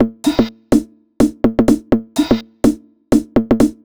cch_jack_percussion_loop_wax_125.wav